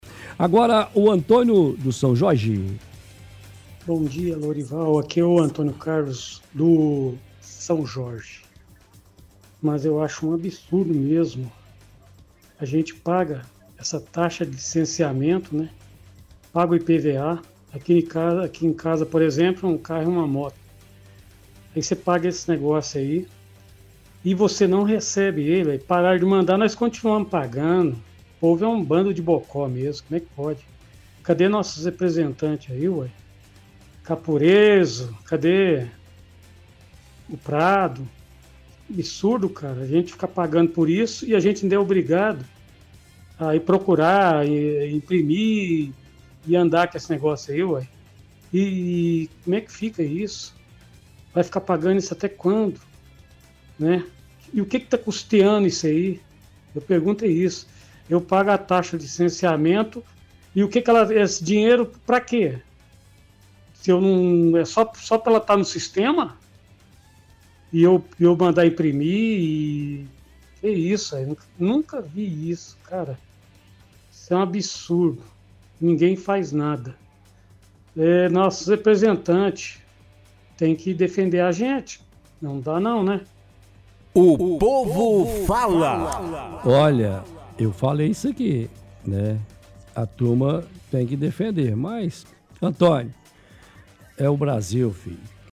– Ouvinte do bairro São Jorge diz que é um absurdo os impostos que pagamos nos veículos e não recebemos os documentos, somos obrigados a imprimir.